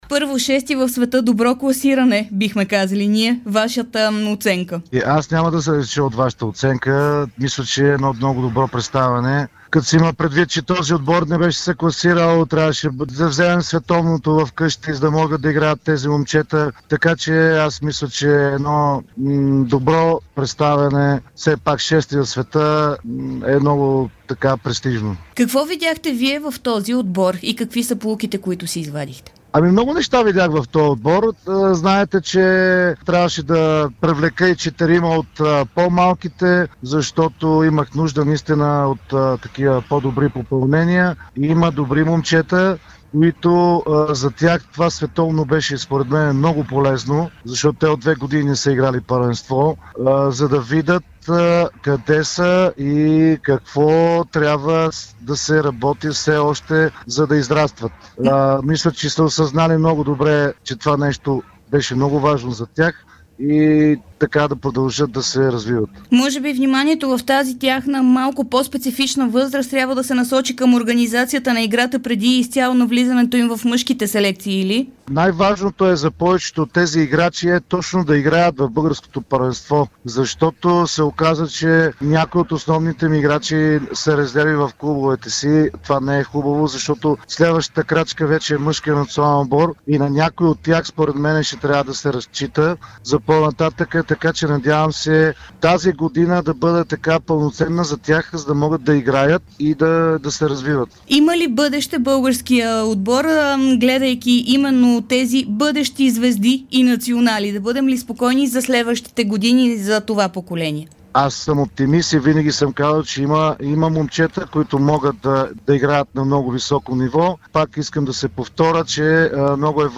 даде специално интервю за Дарик радио след края на Световното първенство. Там отборът завърши на шесто място в крайното класиране.